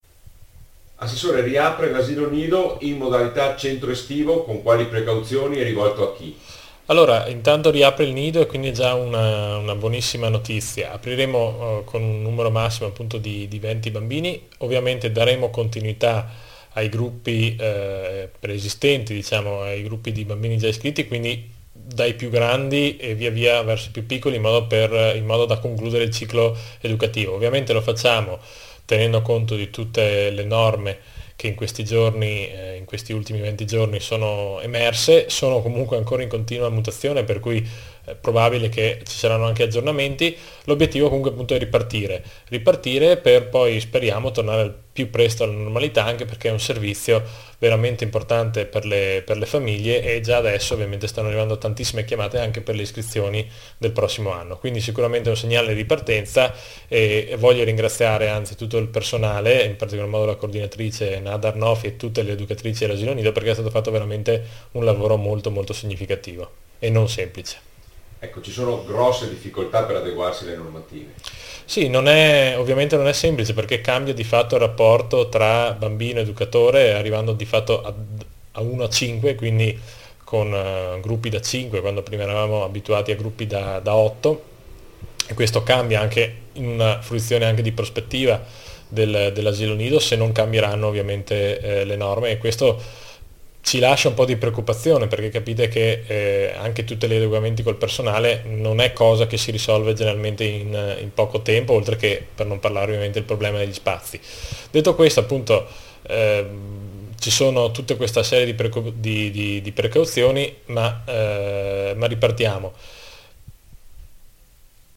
AI MICROFONI DI RADIO PIU’ L’ASSESSORE ALL’ISTRUZIONE ALESSANDRO DEL BIANCO